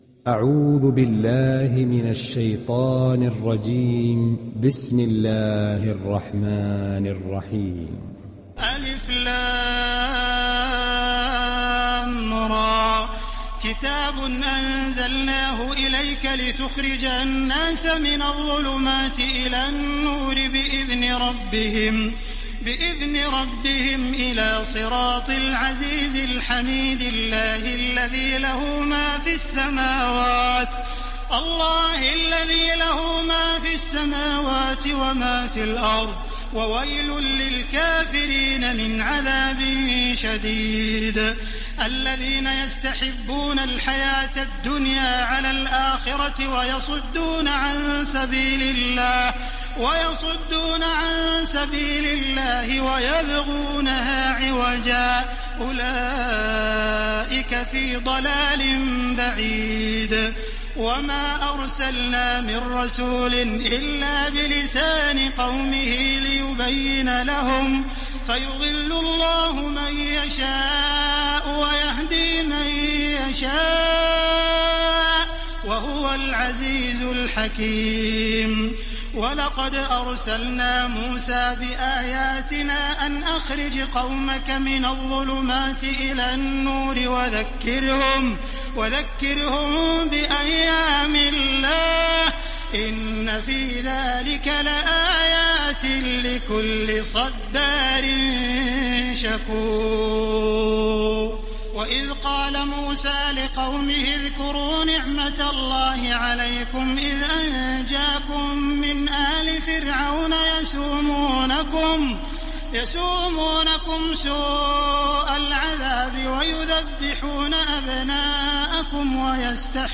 Sourate Ibrahim mp3 Télécharger Taraweeh Makkah 1430 (Riwayat Hafs)
Télécharger Sourate Ibrahim Taraweeh Makkah 1430